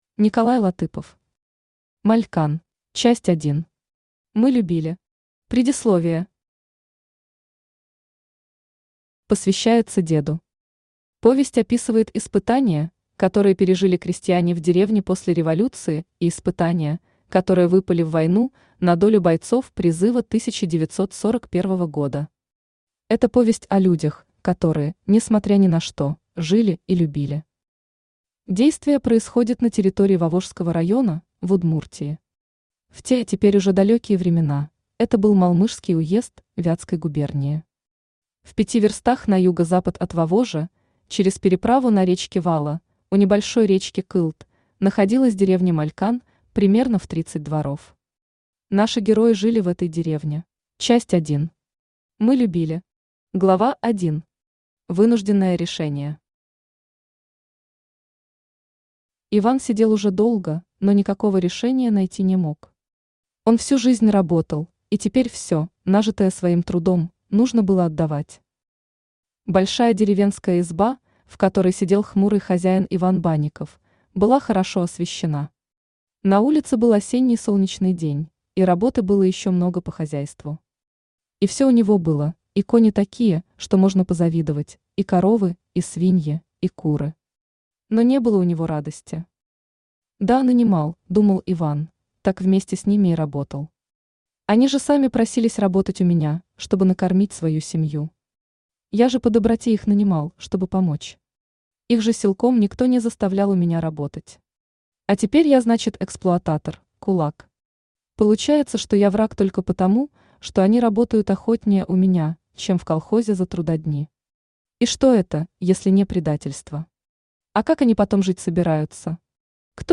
Aудиокнига Малькан Автор Николай Латыпов Читает аудиокнигу Авточтец ЛитРес.